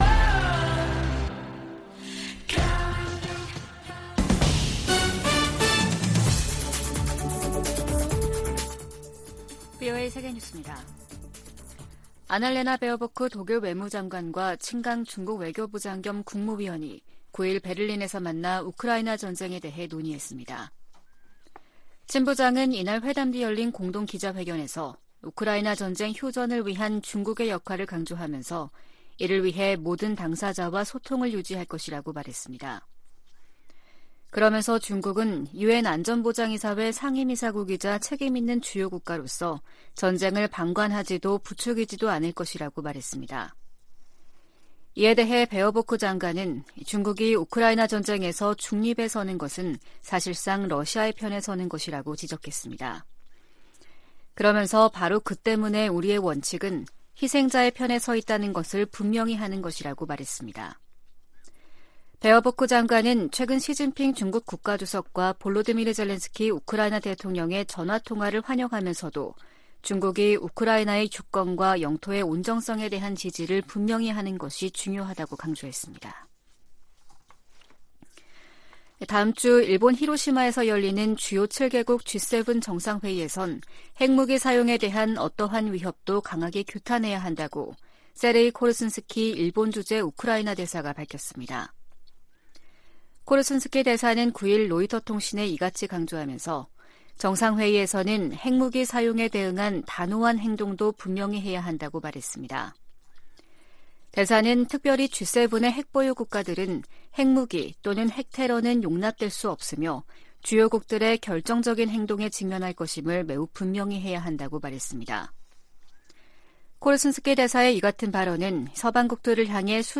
VOA 한국어 아침 뉴스 프로그램 '워싱턴 뉴스 광장' 2023년 5월 10일 방송입니다. 윤석열 한국 대통령과 기시다 후미오 일본 총리가 7일 북한이 제기하는 위협을 거론하며 미한일 3국 공조의 중요성을 강조했습니다. 한일 셔틀외교가 복원됨에 따라 북한 핵 위협에 대응한 미한일 안보 협력이 한층 강화될 것으로 보입니다. 미 국무부가 대북제재를 성실히 이행하고 있다는 중국의 주장을 일축하고, 대북 영향력을 행사할 필요가 있다고 강조했습니다.